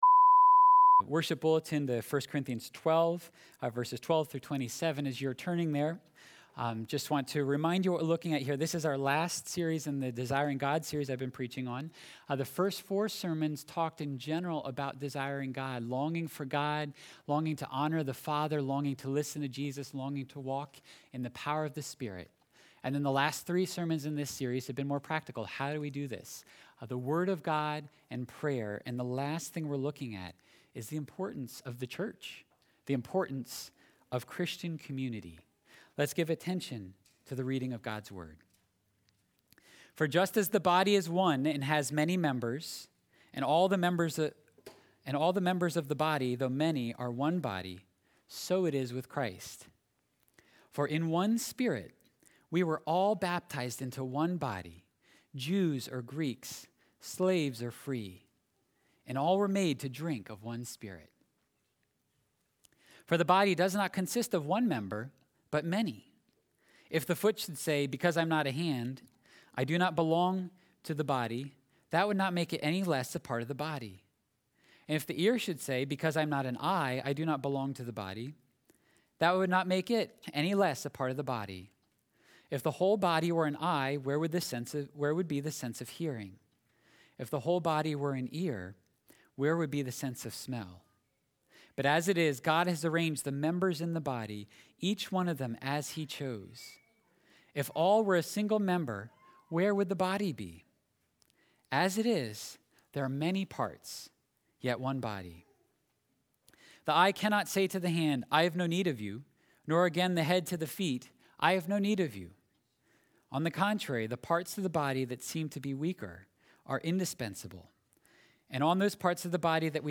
Sunday Worship, May 31. Sermon: Desiring God in Community